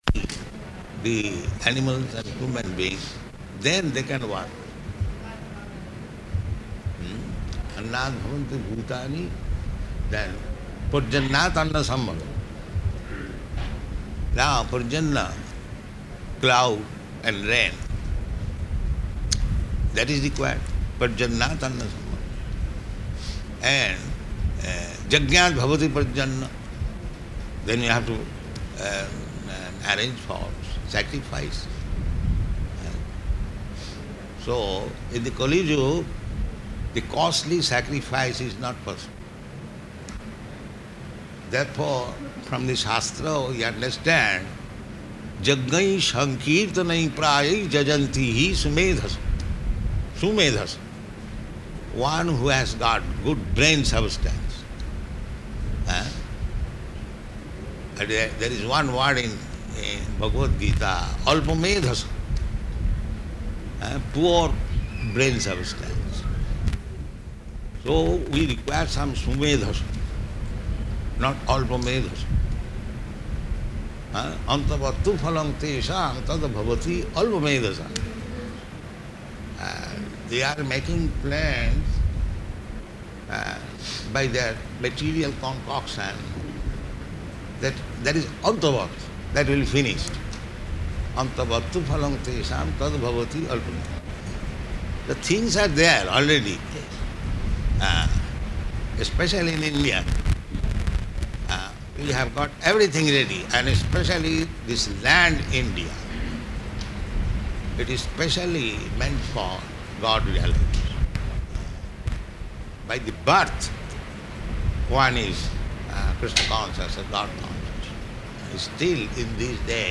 Room Conversation
Location: Vṛndāvana